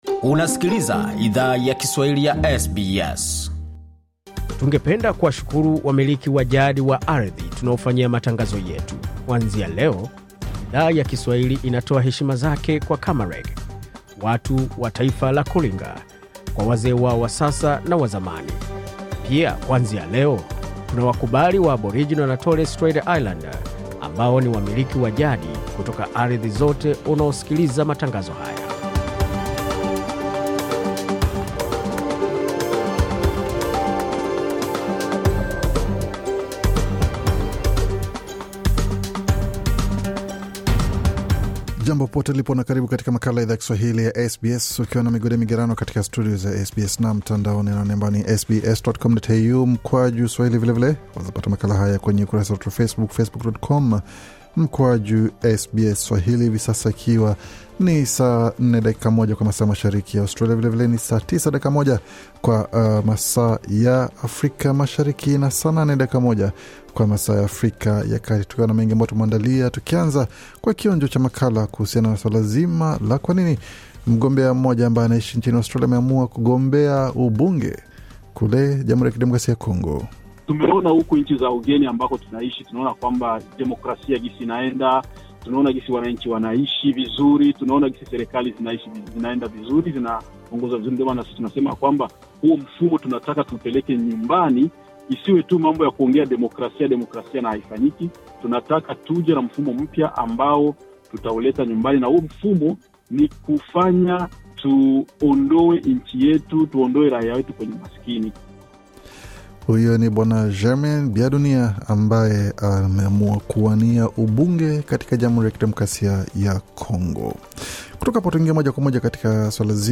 Taarifa ya Habari 23 Julai 2023